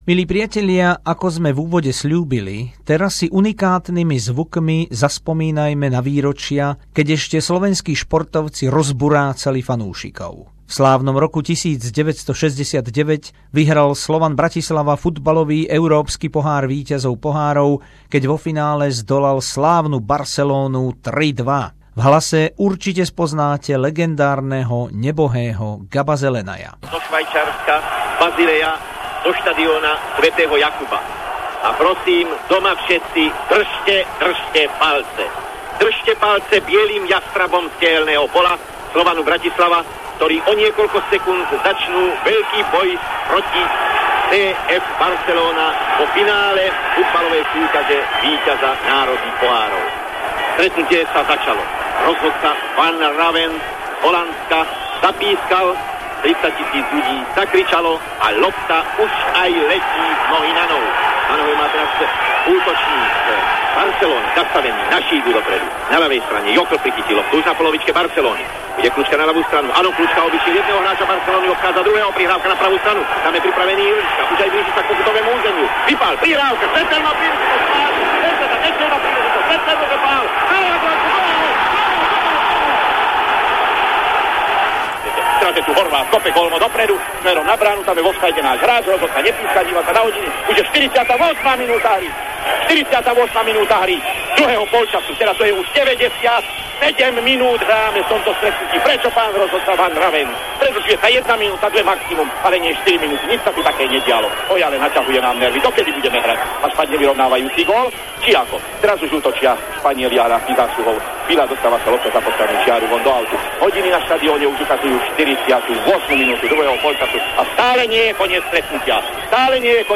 Po hanbe a páde slovenského hokeja na MS 2017 spomienky na slávnejšie časy nášho hokeja a futbalu s pôvodným komentárom našich legendárnych reportérov:- 1969 finále PVP Slovan - Barcelona- 1969 MS v hokeji ČSSR - ZSSR- 2002 finále MS v hokeji Slovensko - Rusko